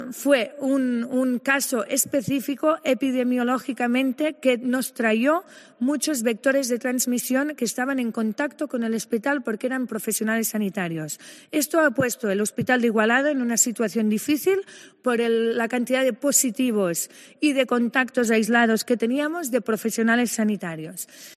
Alba Vergés, consellera de Salut, explica la complicada situación que vive el Hospital de Igualada